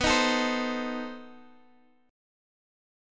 B7b9 chord